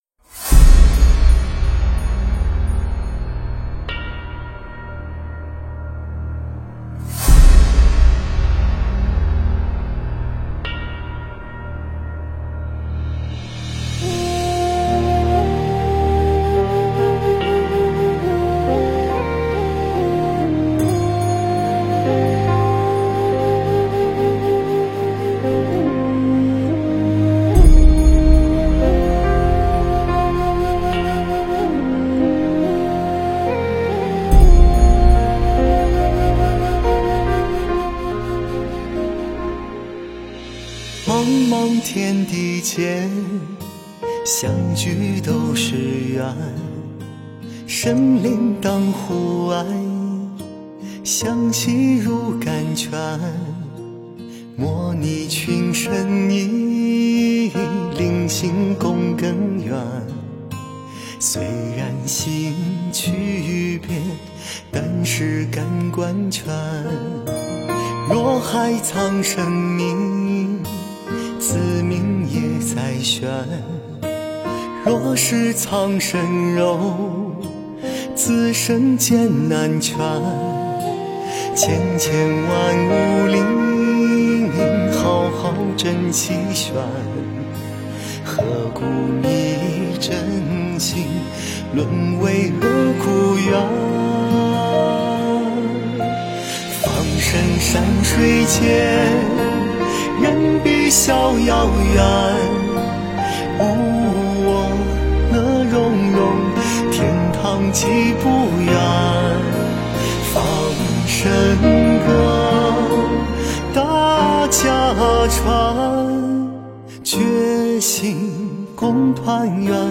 佛音 凡歌 佛教音乐 返回列表 上一篇： 我佛慈悲